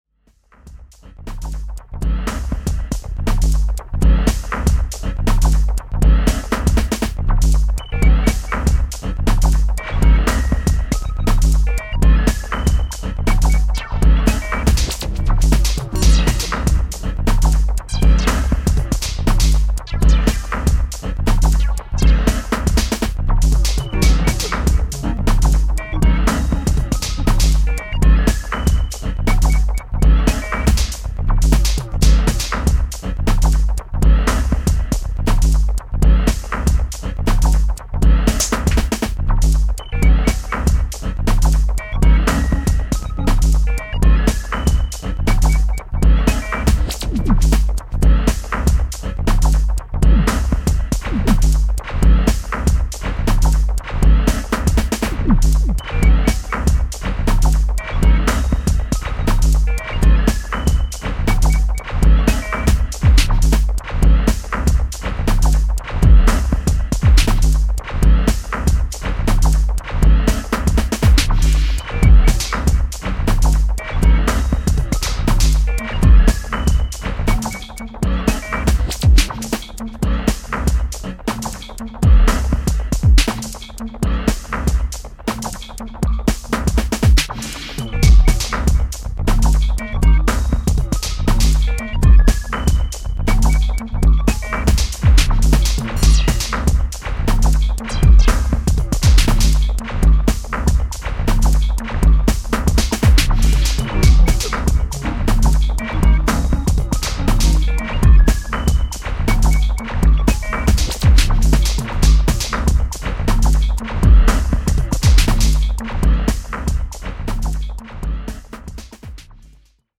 Electro House Techno